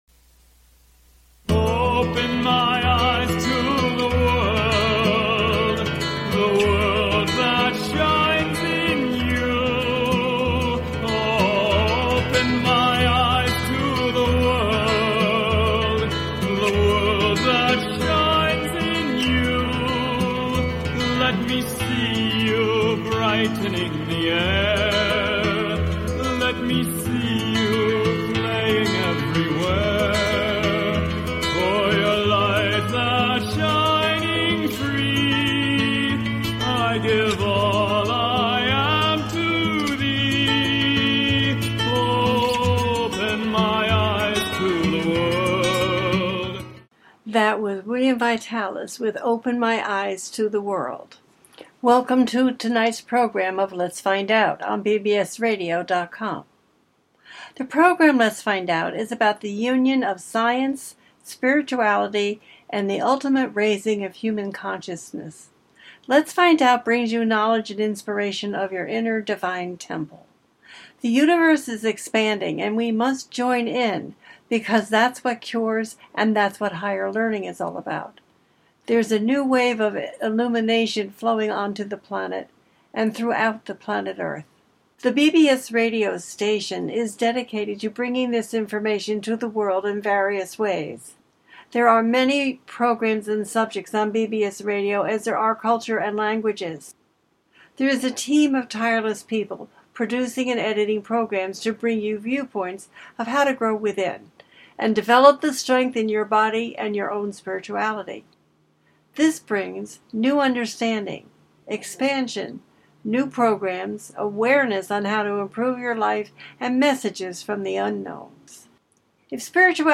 This show brings a series of fascinating interviews with experts in the field of metaphysics.